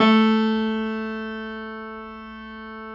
53h-pno09-A1.aif